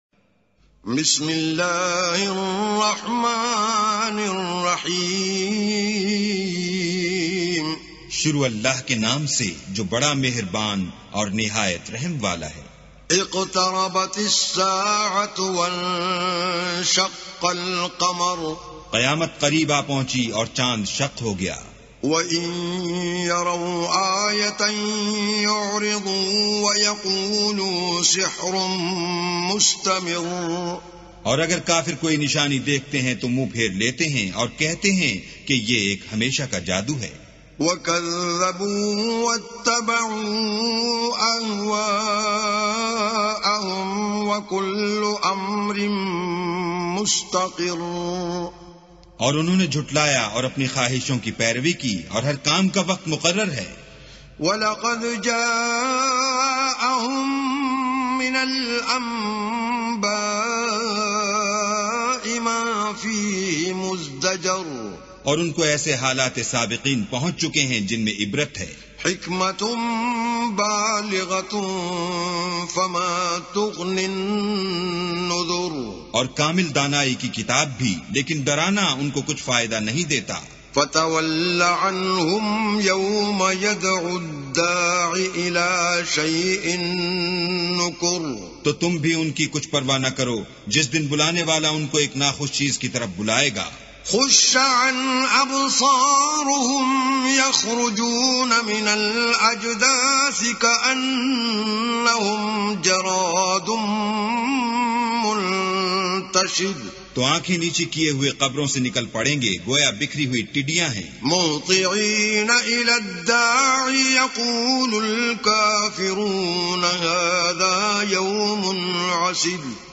Quran tilawat / Recitation of Surah Al Qamar